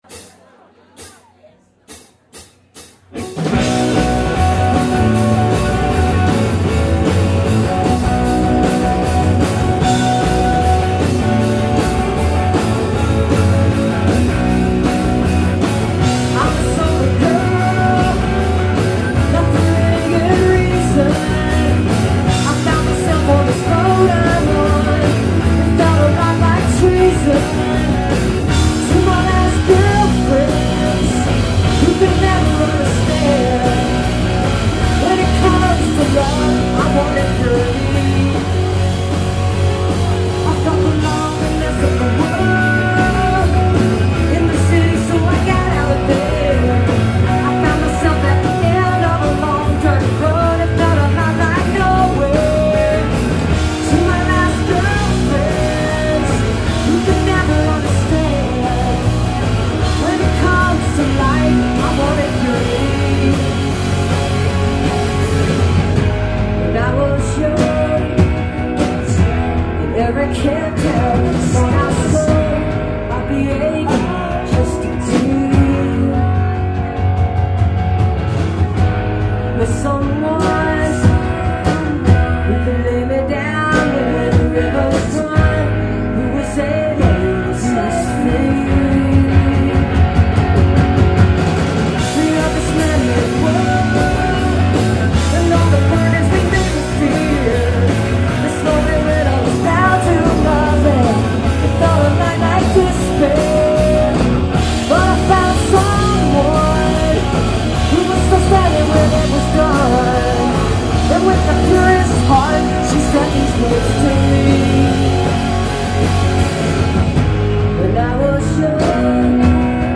the echo lounge - atlanta, georgia